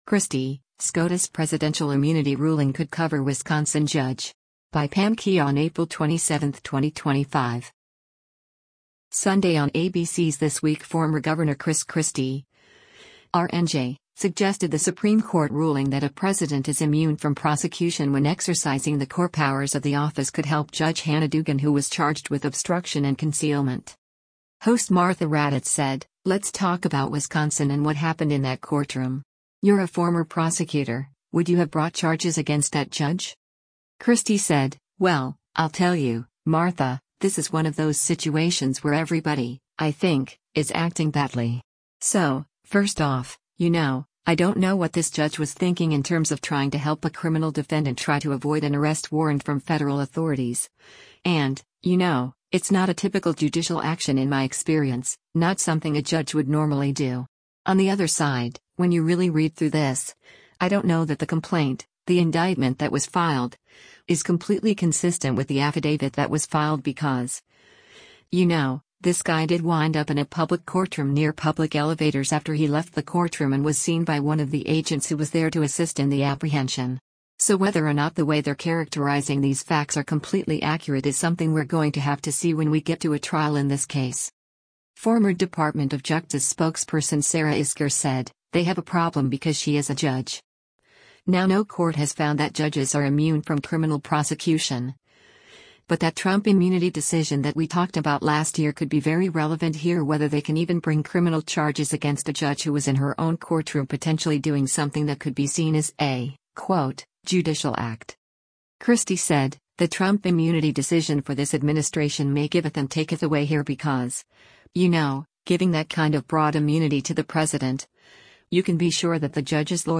Sunday on ABC’s “This Week” former Gov. Chris Christie (R-NJ) suggested the Supreme Court ruling that a president is immune from prosecution when exercising the core powers of the office could help Judge Hannah Dugan who was charged with obstruction and concealment.